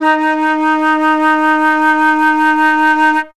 51c-flt15-D#3.wav